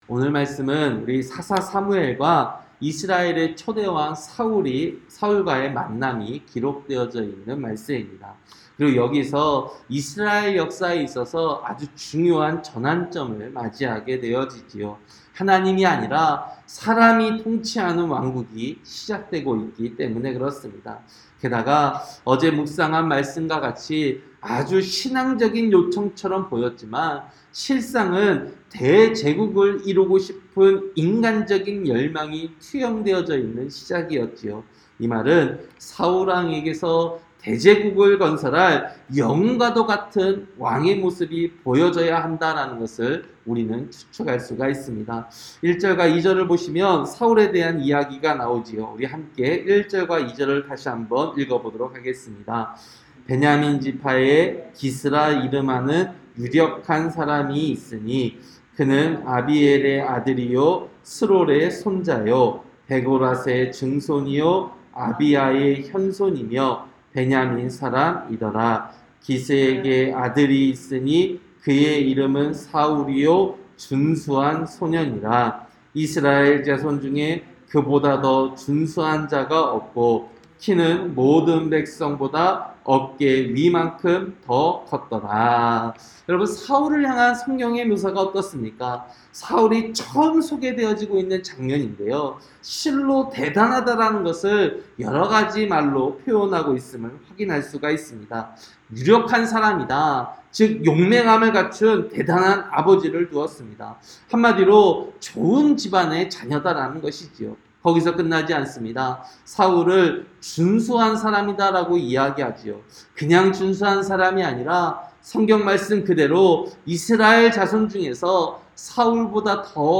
새벽설교-사무엘상 9장